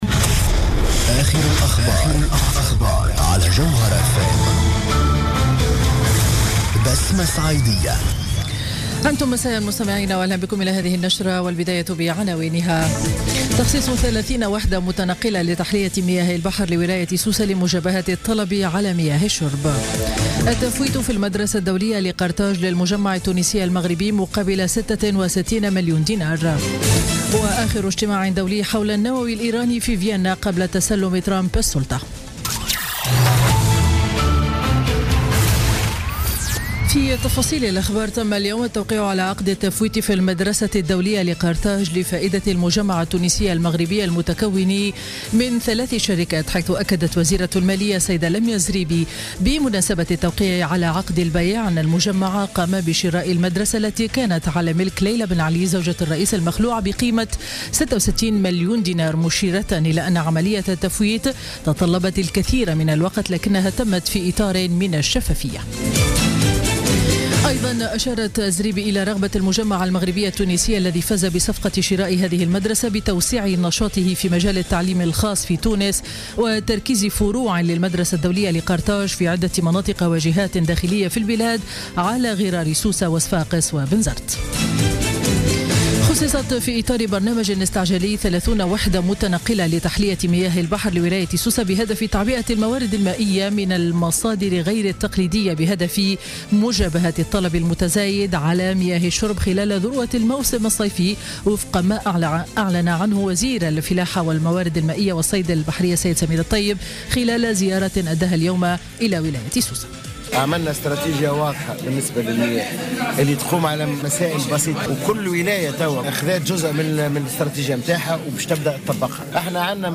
نشرة أخبار السابعة مساء ليوم الثلاثاء 10 جانفي 2017